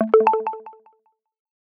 HTC Desire Bildirim Sesleri